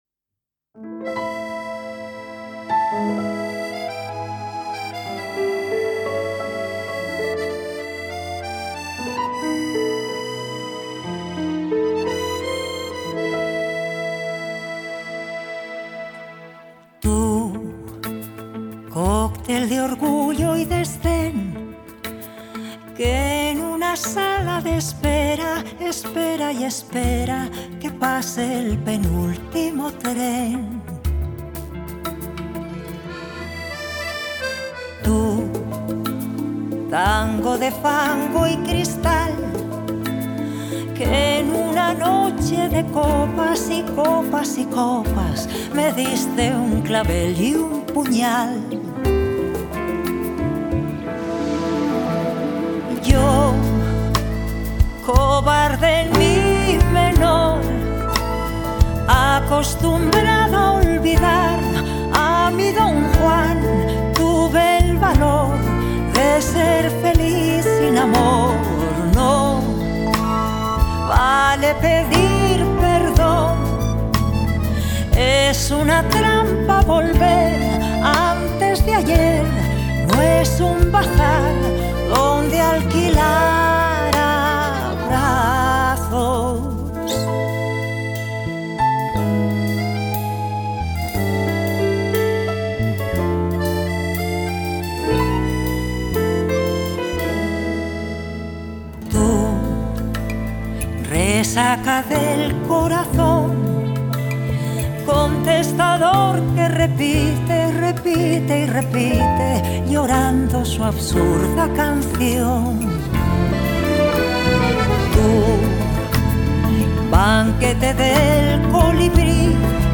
Spain • Genre: Pop